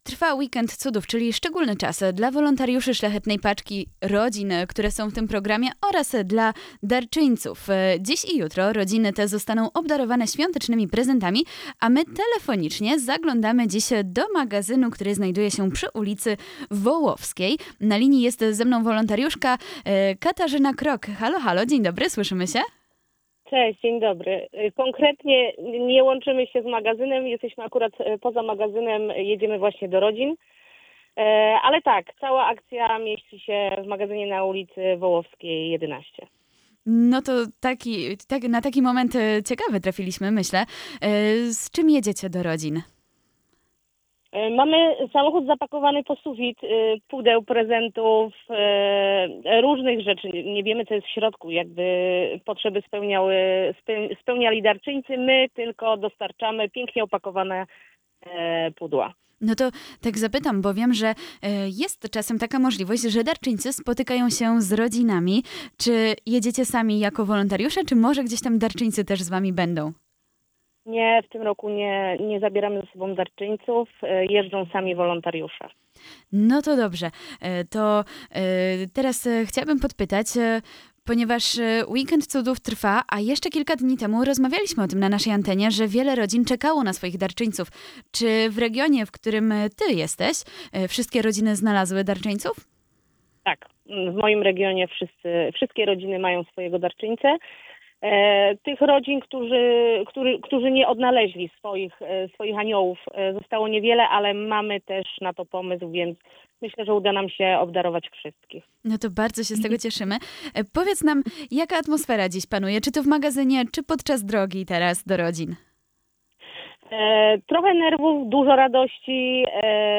Laczenie-z-magazynem-Szlachetnej-Paczki.mp3